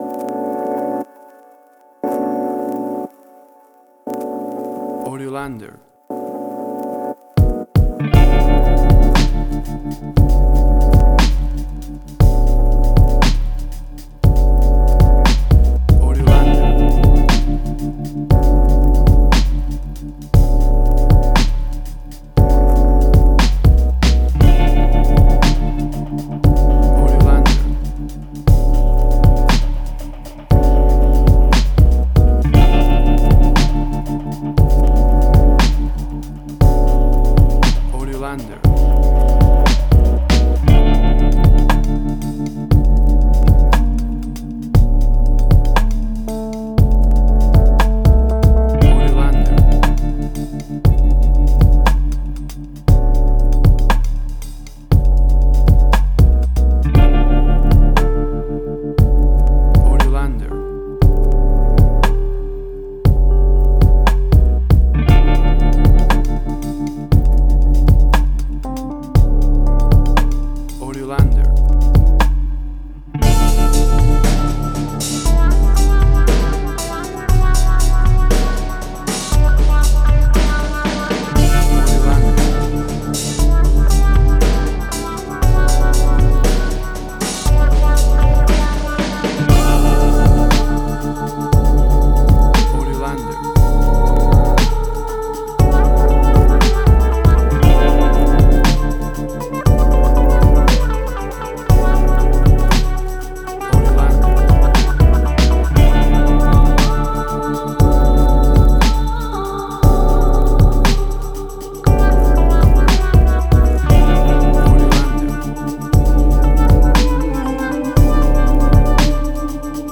Chill Out.
Tempo (BPM): 59